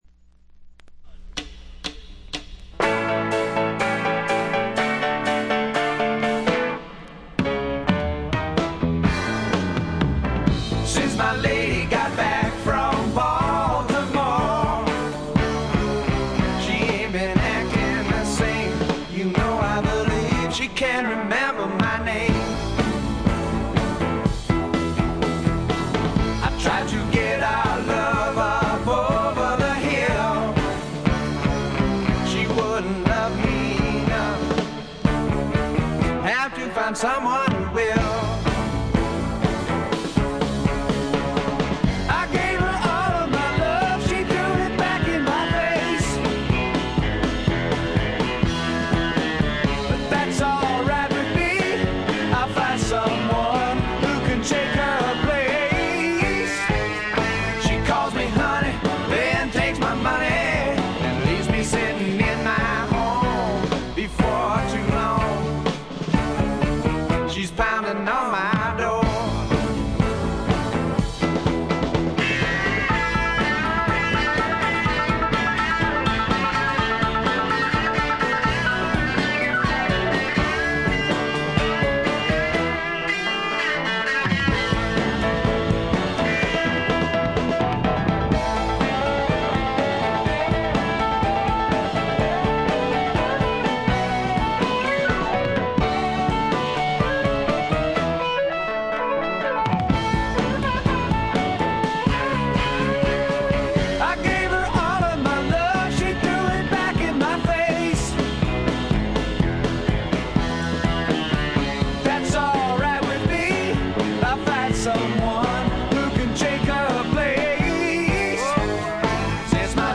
静音部でチリプチが聴かれますがおおむね良好に鑑賞できると思います。
試聴曲は現品からの取り込み音源です。
Instrumental